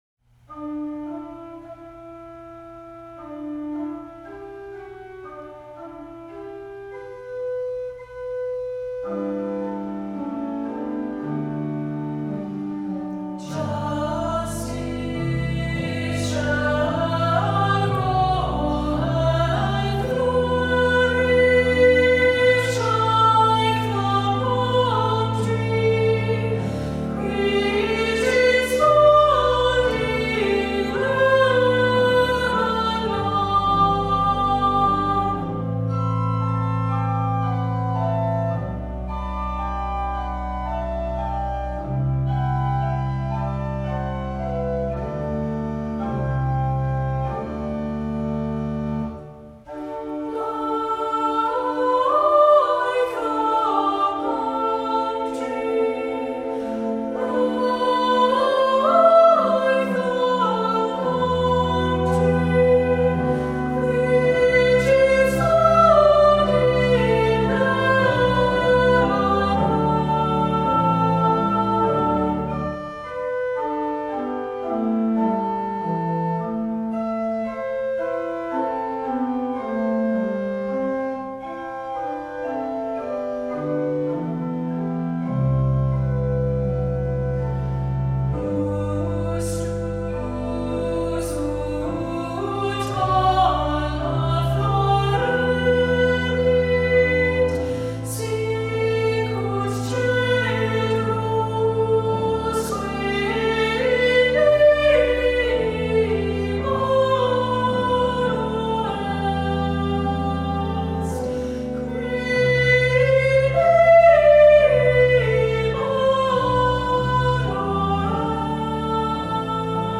Voicing: Unison choir